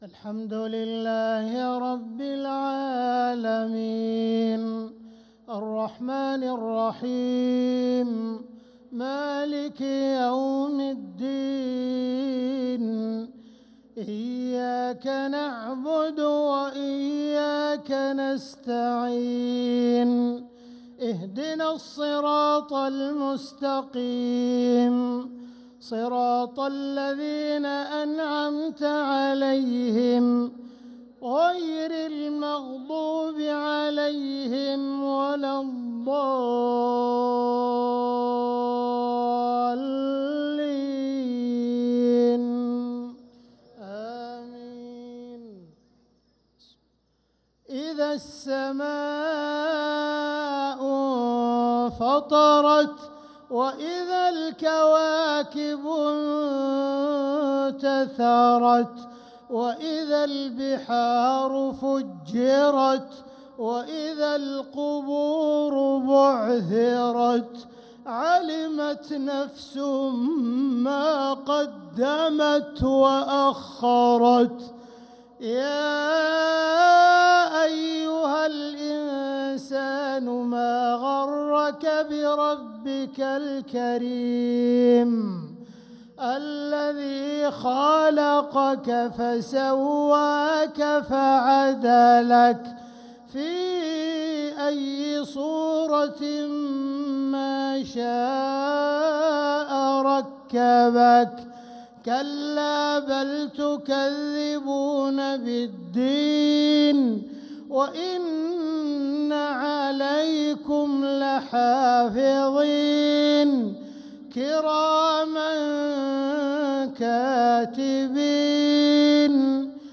مغرب الأحد 2-9-1446هـ سورة الإنفطار كاملة | Maghrib prayer Surat al-Infitar 2-3-2025 > 1446 🕋 > الفروض - تلاوات الحرمين